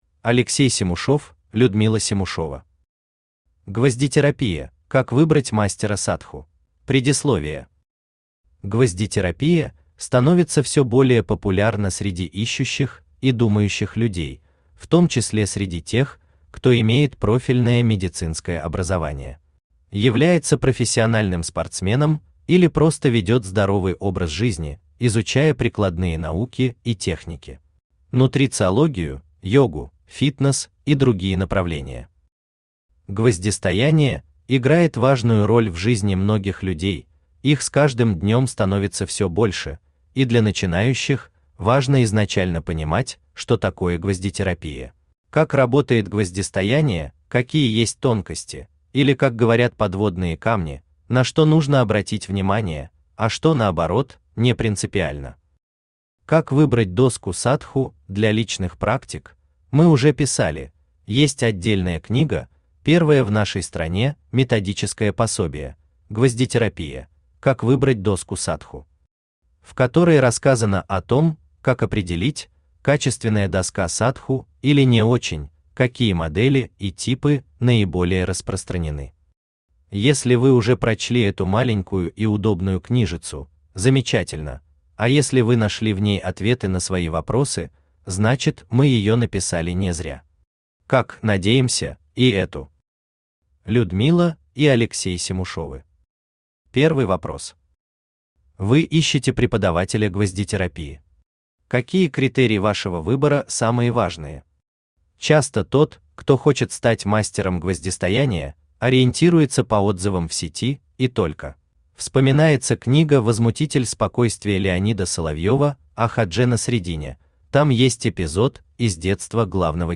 Aудиокнига Гвоздетерапия: Как выбрать Мастера Садху? Автор Алексей Семушев Читает аудиокнигу Авточтец ЛитРес.